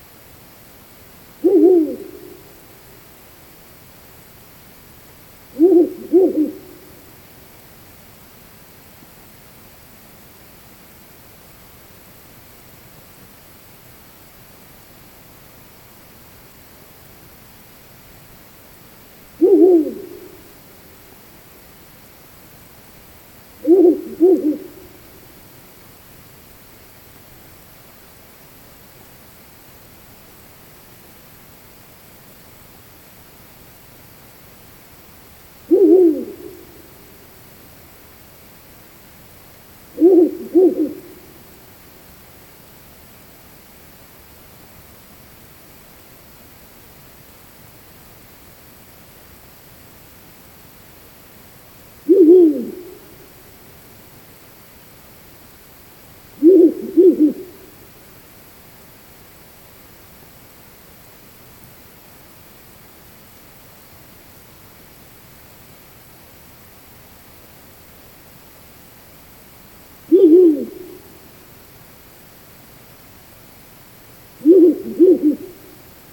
длиннохвостая неясыть, Strix uralensis
СтатусТерриториальное поведение